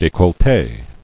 [dé·colle·té]